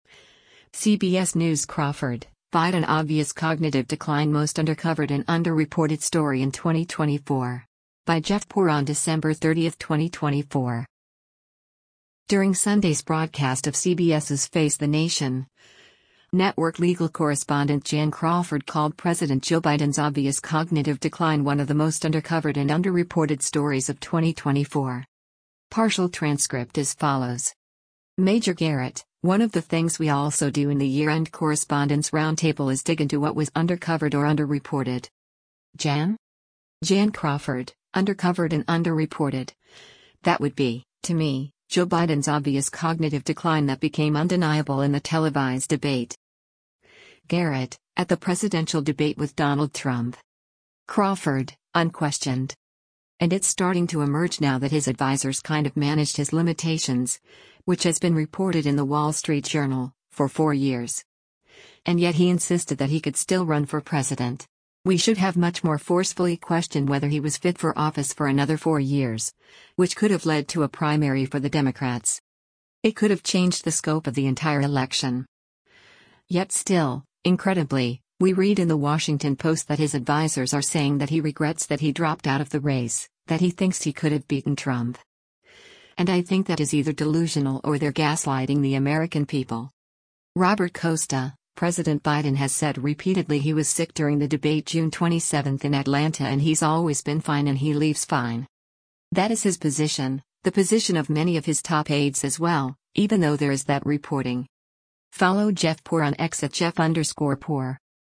During Sunday’s broadcast of CBS’s “Face the Nation,” network legal correspondent Jan Crawford called President Joe Biden’s “obvious cognitive decline” one of the most undercovered and underreported stories of 2024.